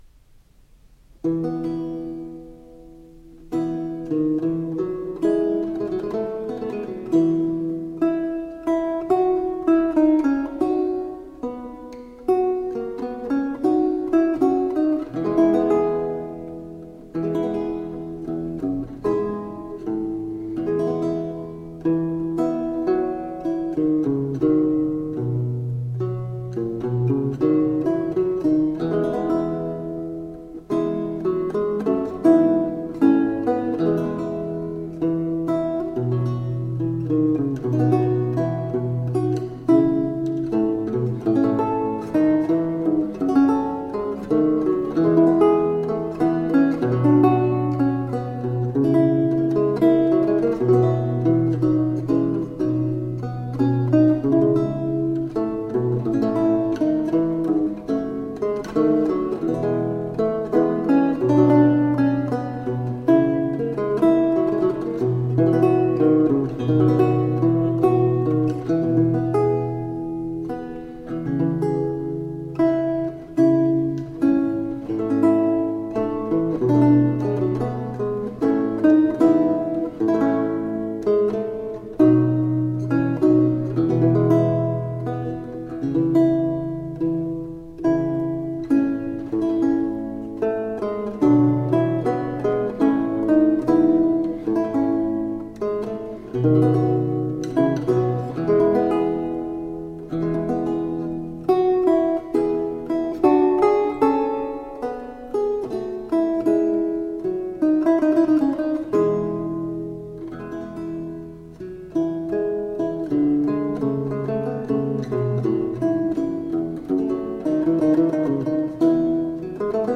Renaissance lute